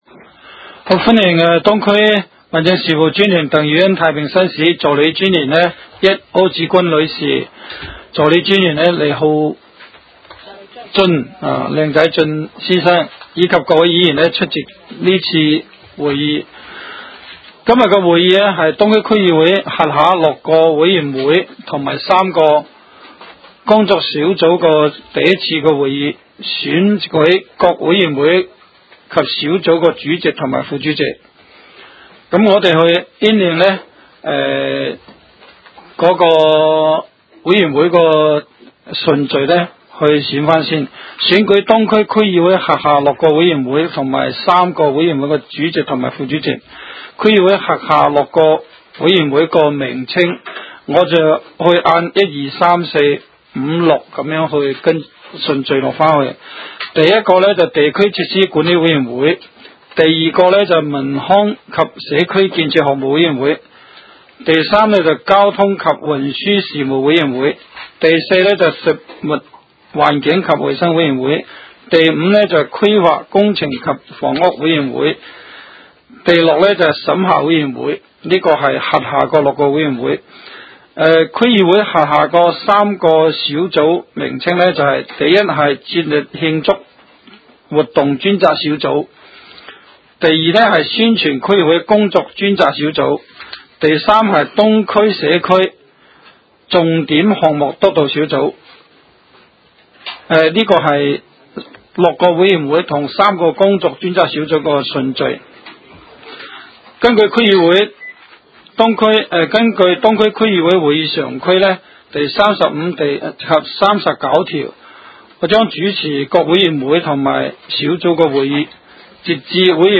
委员会会议的录音记录
地点: 香港西湾河太安街29号 东区法院大楼11楼 东区区议会会议室